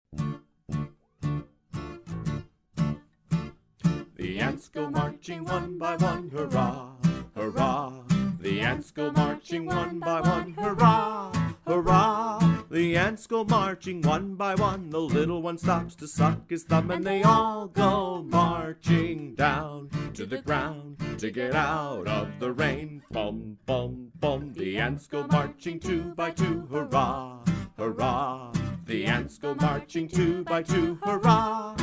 Vocal Song Downloads